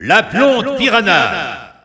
Announcer pronouncing Piranha Plant's name in French (PAL).
Category:Piranha Plant (SSBU) Category:Announcer calls (SSBU) You cannot overwrite this file.
Piranha_Plant_French_EU_Alt_Announcer_SSBU.wav